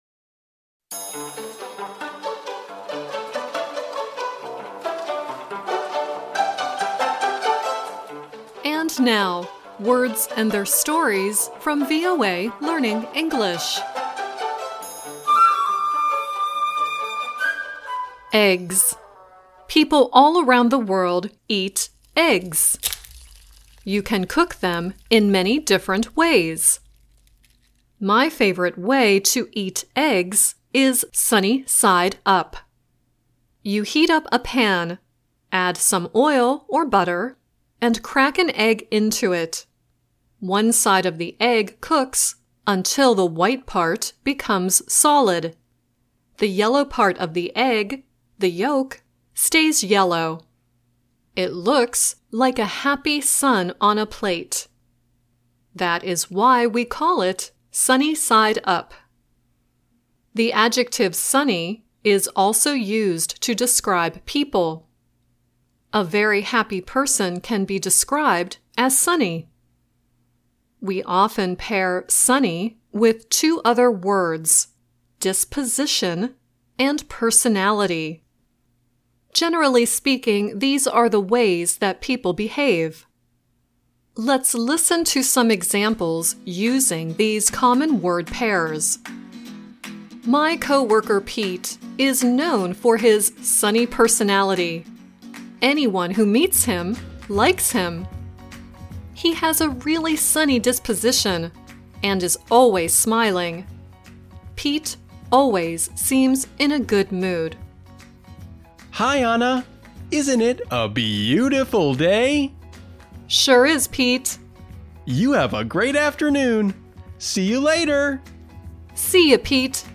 The song at the end is June Carter Cash singing "Keep on the Sunny Side."